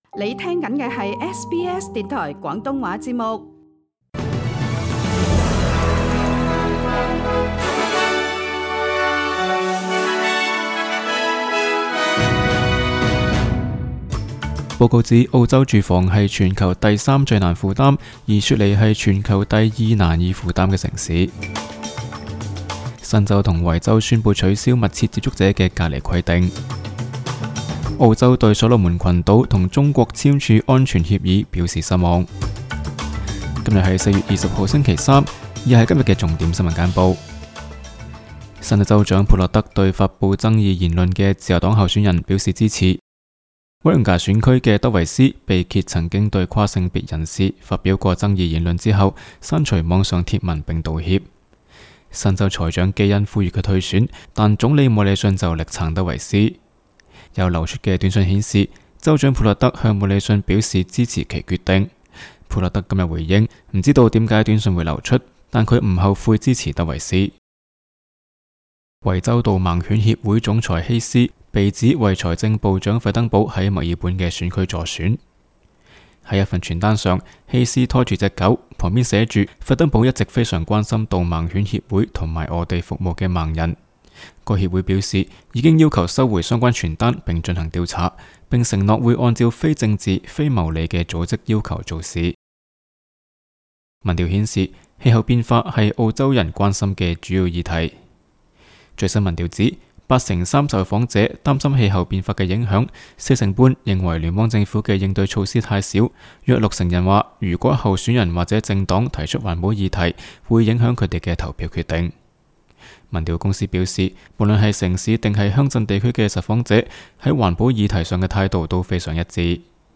SBS 新闻简报（4月20日）
SBS 廣東話節目新聞簡報 Source: SBS Cantonese